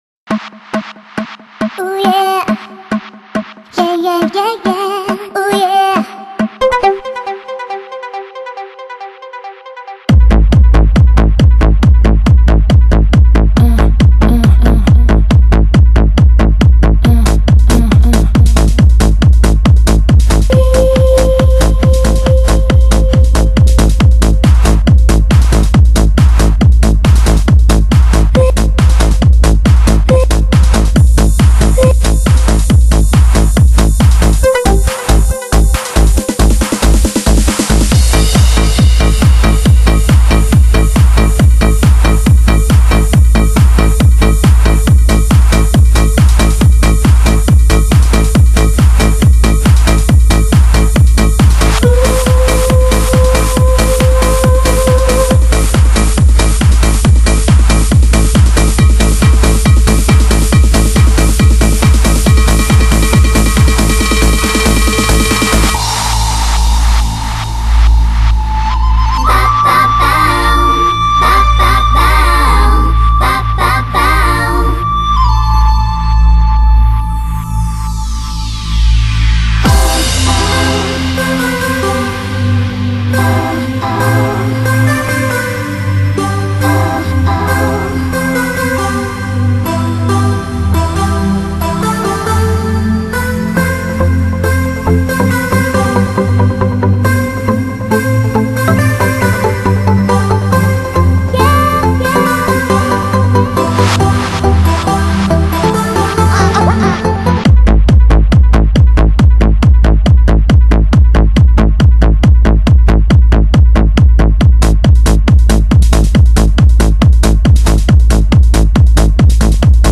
最新最红的震撼舞曲，令人无法抗拒的诱惑，无比高潮的舞曲精品。